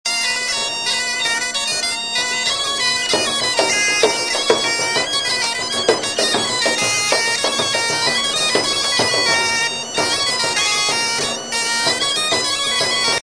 avec le mélange couple biniou bombarde plus tambourin.